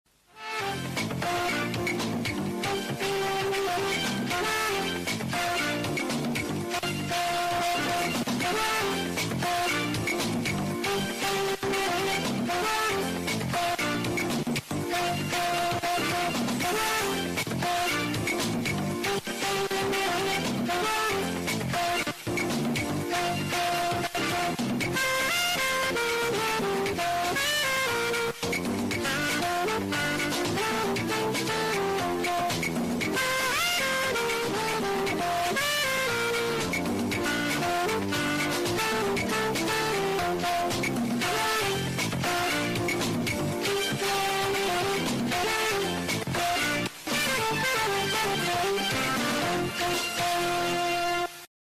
(Full intro)